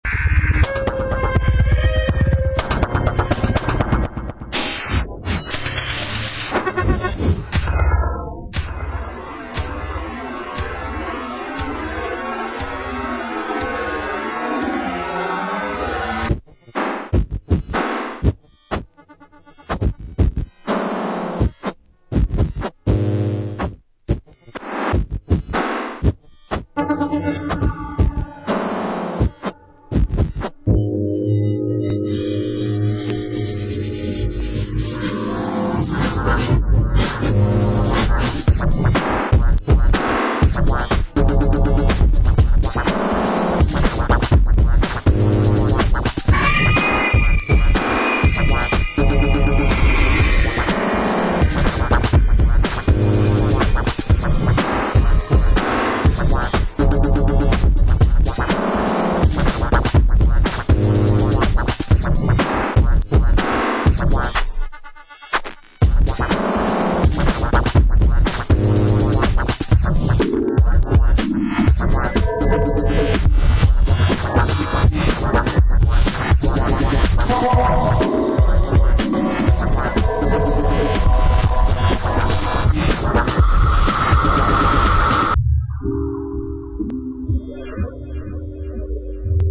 Crazy electronical sh*t
complex rhythms with crazy vocals samples and nice melodies.